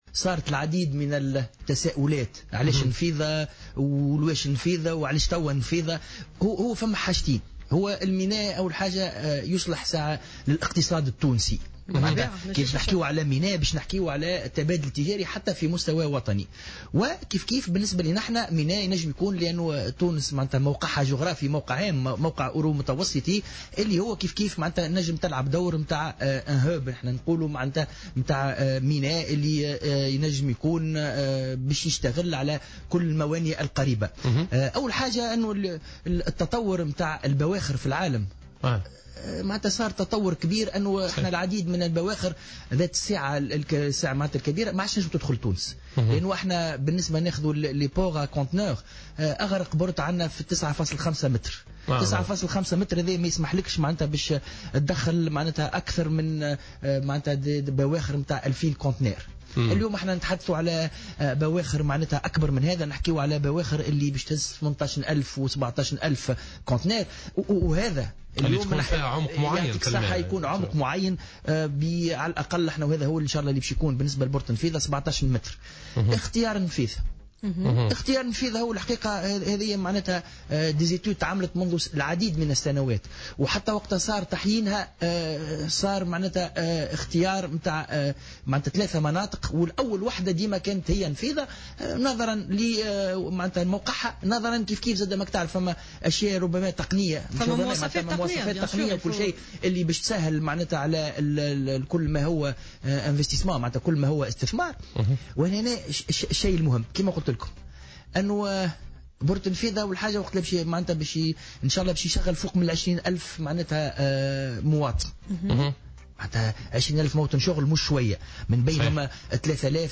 وأضاف بن أحمد في مداخلة له اليوم على "الجوهرة أف أم" أن تم الانتهاء من الدراسات المتعلقة بهذا المشروع والذي سيمكن من توفير أكثر من 20 ألف موطن شغل بينها 3 آلاف فرصة عمل بصفة مباشرة.